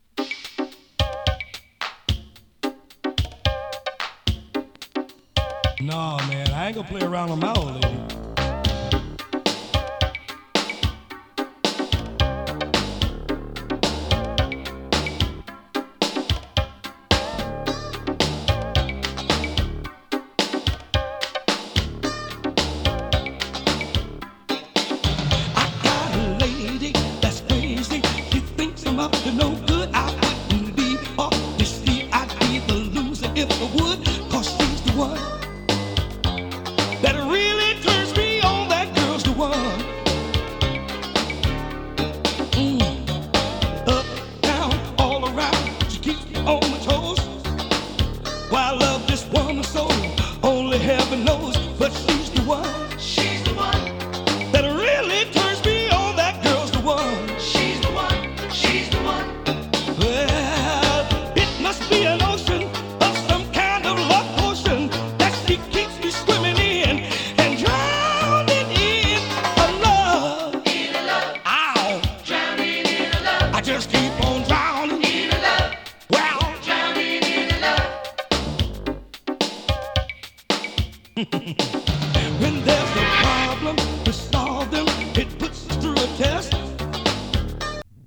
モダンなミディアム・ファンク